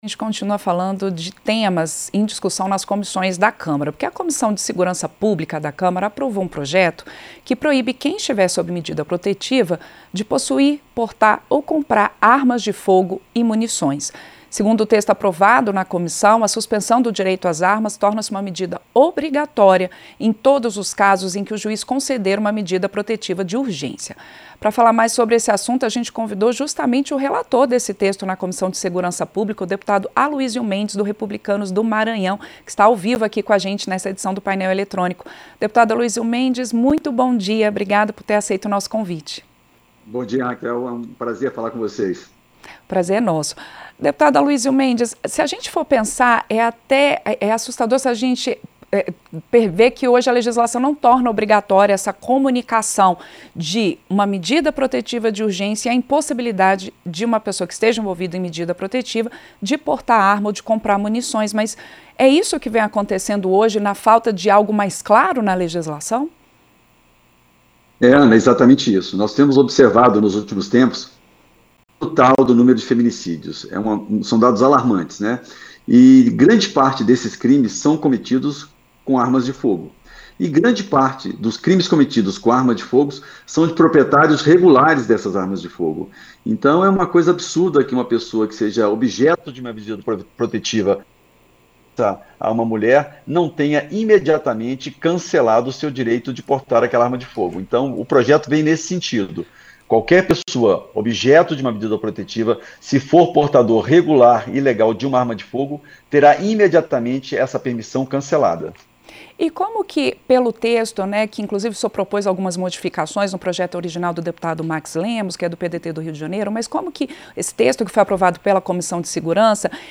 • Entrevista -Dep. Aluisio Mendes (Rep-MA)
Programa ao vivo com reportagens, entrevistas sobre temas relacionados à Câmara dos Deputados, e o que vai ser destaque durante a semana.